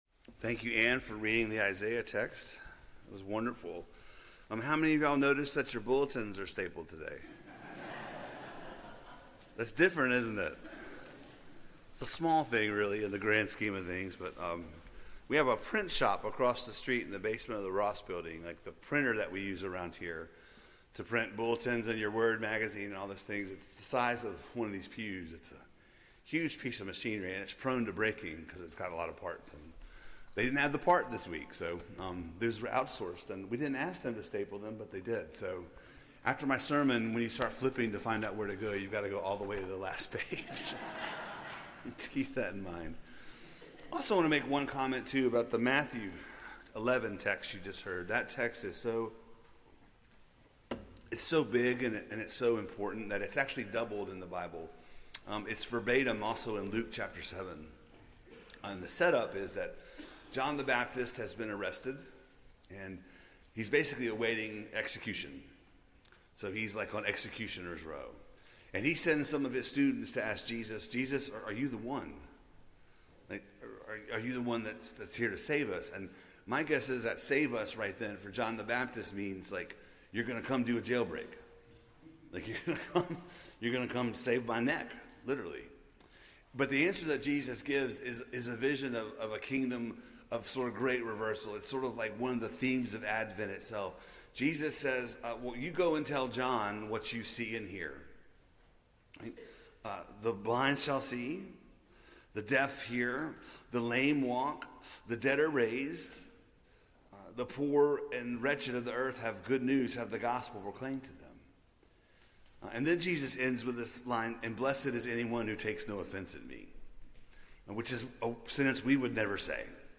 Bulletin Sermon